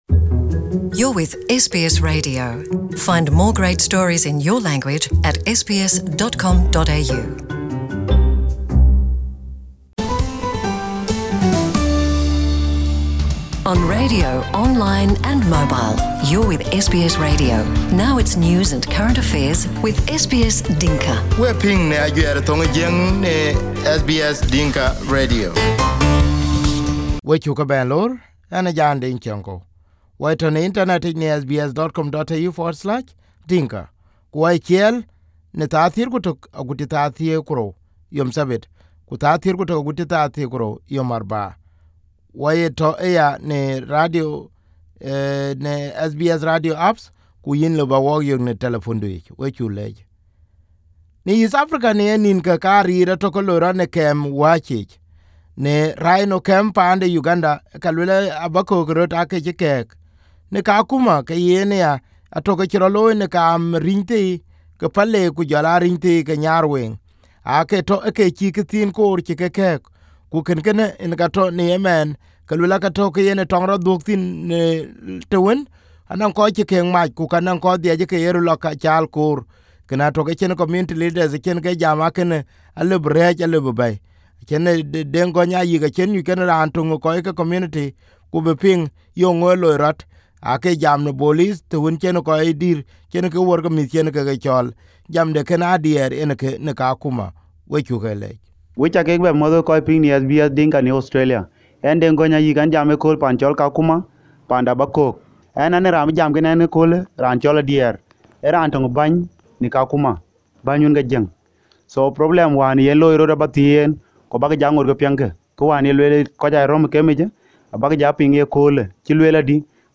Kakuma Leader in Kakuma Refugee camp Source